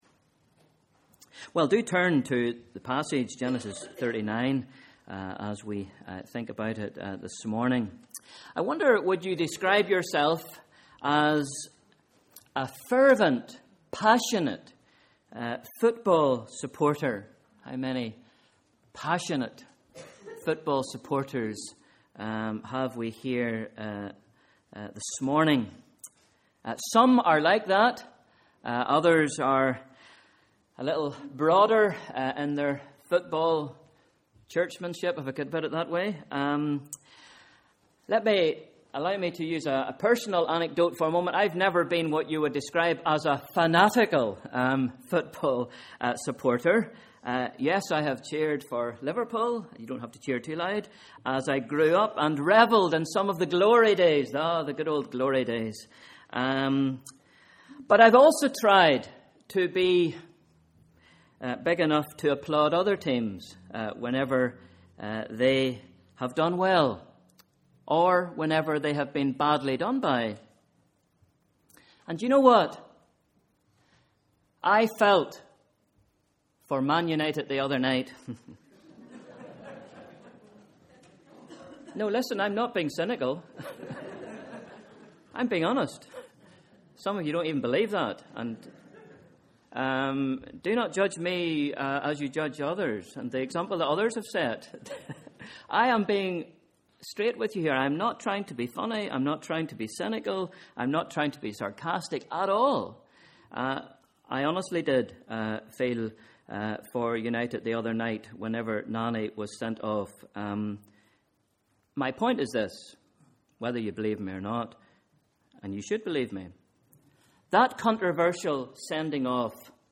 Sunday 10th March: Morning Service